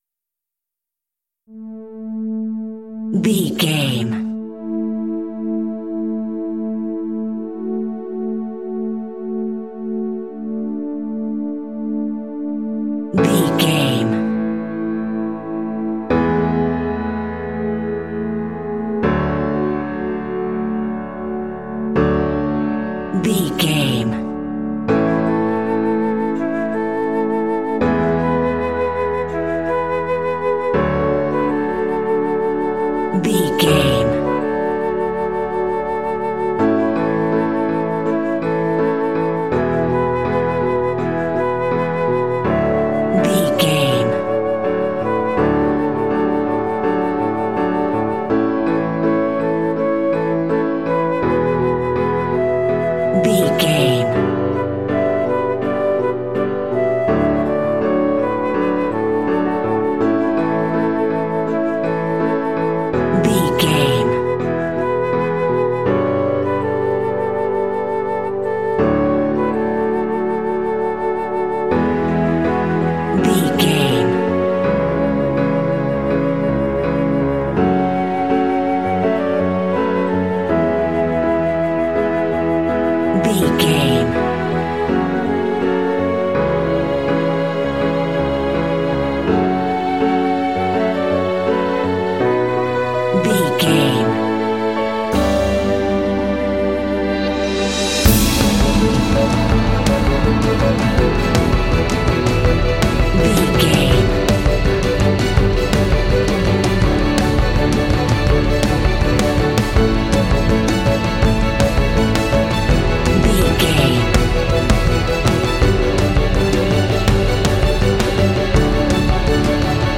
Ionian/Major
meditative
melancholy
smooth
bright
inspirational
flute
strings
orchestra
percussion
electric guitar
bass guitar
film score
instrumental
cinematic